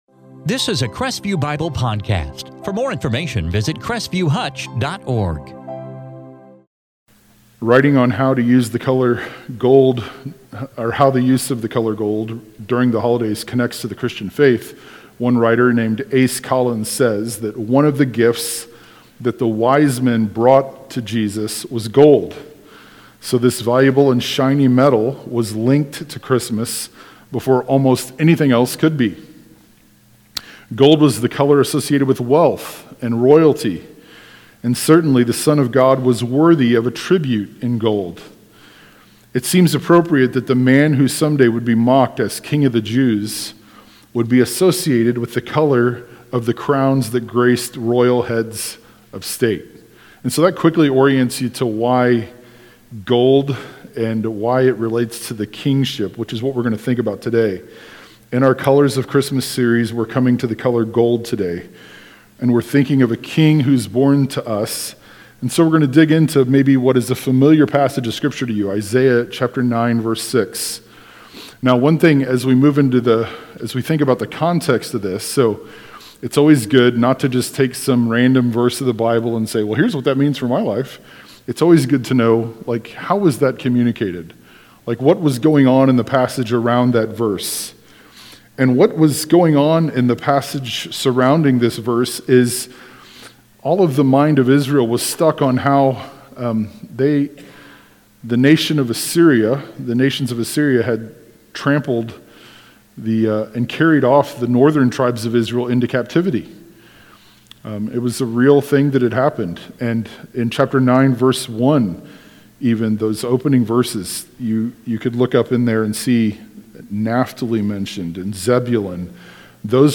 Topic Advent